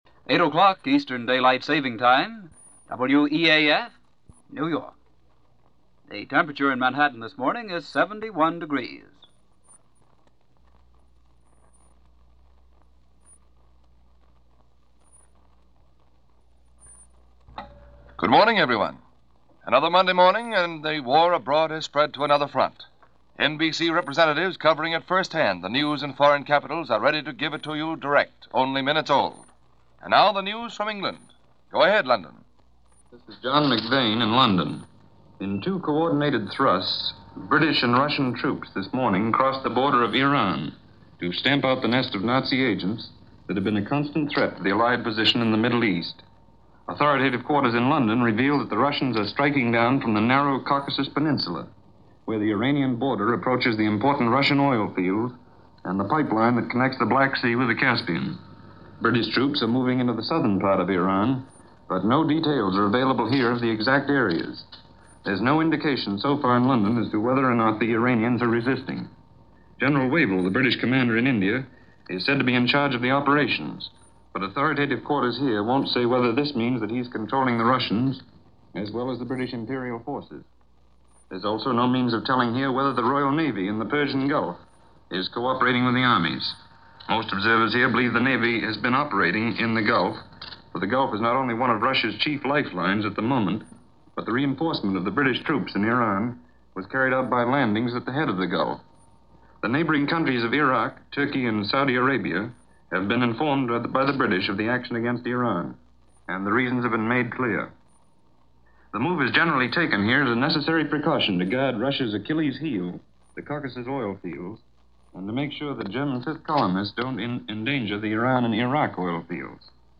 August 25, 1941 – News Of The World – NBC Red Network – Gordon Skene Sound Collection –